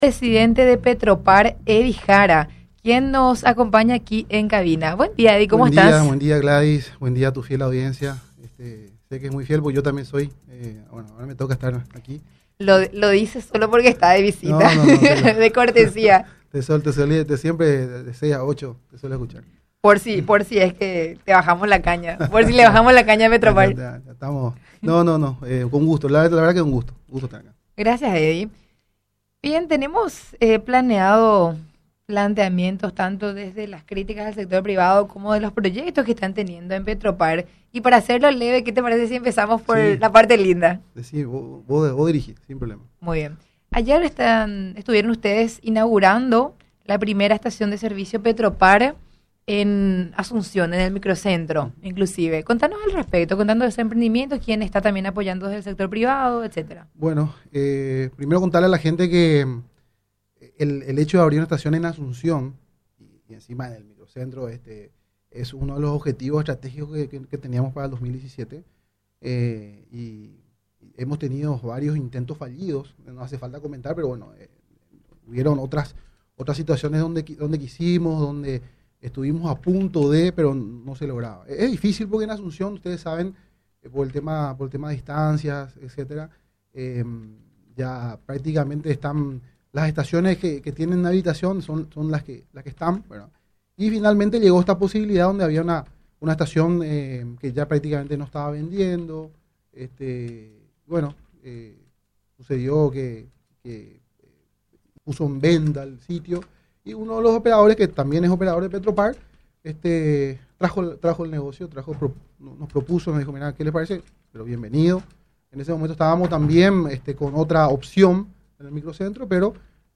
El presidente de la estatal visitó los estudios de la R800AM y respondió a las críticas del sector privado, que en reiteradas ocasiones denunció que Petropar realizaba una competencia desleal al colocar estaciones de servicio, vendiendo combustibles a menor costo.